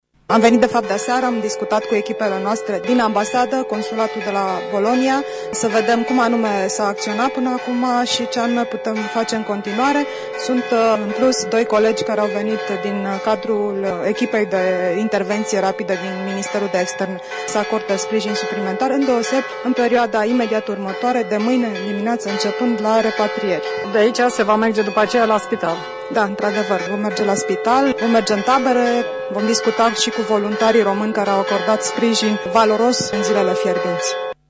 La slujba oficiată de episcopul ortodox Siluan în parohia de la Rieti a luat parte şi ministrul delegat pentru românii de pretutindeni, Maria Ligor: